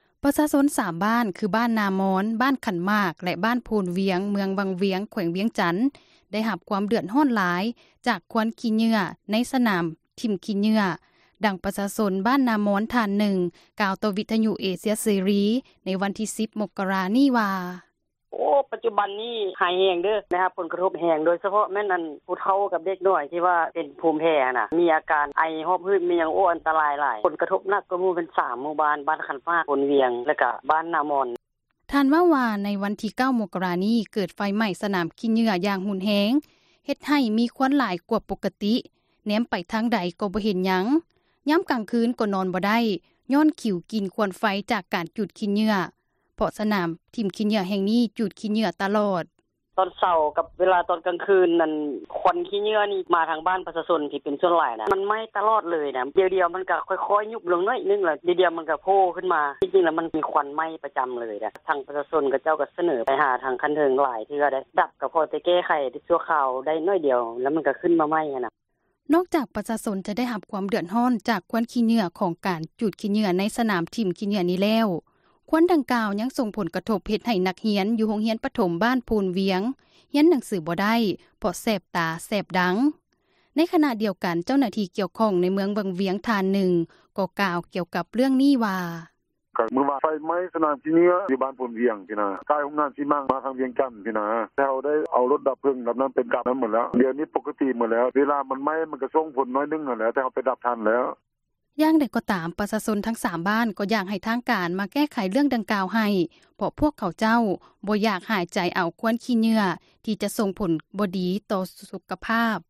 ປະຊາຊົນ 3 ບ້ານ ຄື ບ້ານນາມອນ, ບ້ານຂັນໝາກ ແລະບ້ານໂພນວຽງ ເມືອງວັງວຽງ ແຂວງວຽງຈັນ ໄດ້ຮັບຄວາມເດືອດຮ້ອນຫຼາຍ ຈາກຄວັນຂີ້ເຫຍື້ອ ໃນສນາມຖິ້ມຂີ້ເຫຍື້ອ, ດັ່ງປະຊາຊົນ ບ້ານນາມອນ ທ່ານນຶ່ງ ກ່າວຕໍ່ວິທຍຸເອເຊັຽເສຣີ ໃນວັນທີ່ 10 ມົກກະຣາ ນີ້ວ່າ: